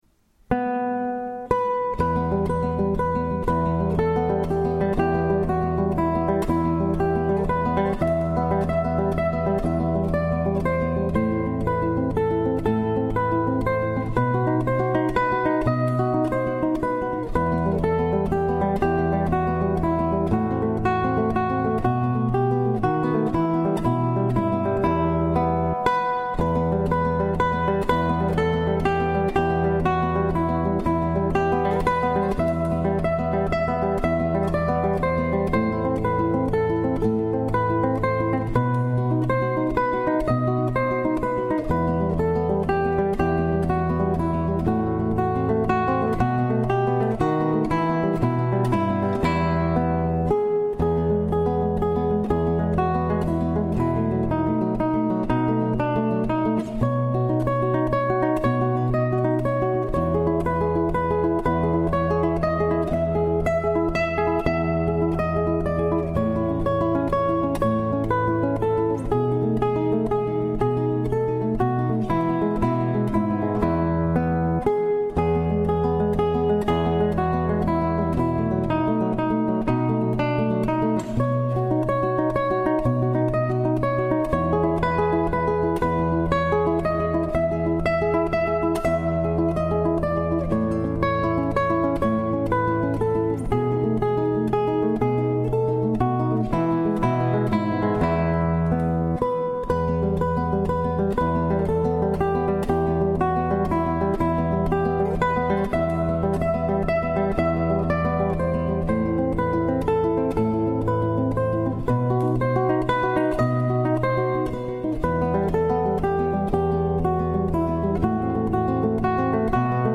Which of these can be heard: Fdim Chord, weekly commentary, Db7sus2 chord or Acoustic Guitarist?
Acoustic Guitarist